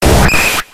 KABUTO.ogg